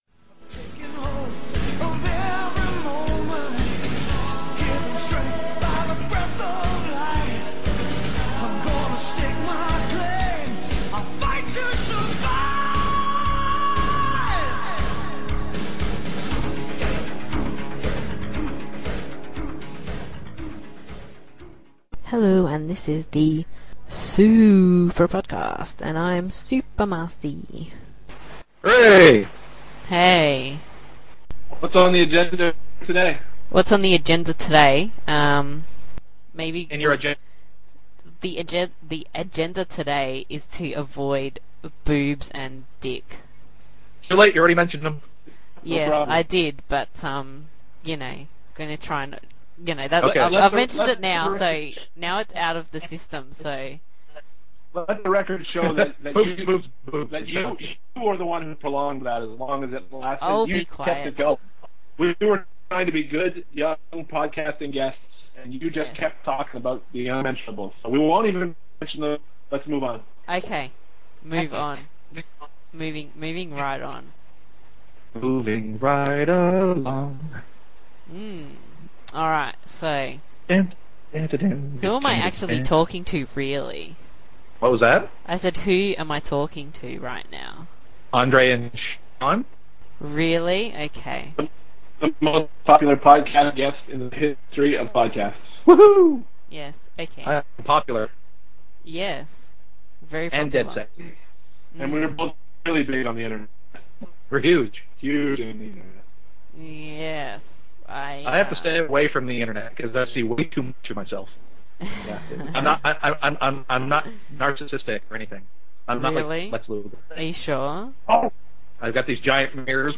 Hopefully you enjoy episode 10 despite the technical issues, I think we got a very solid show.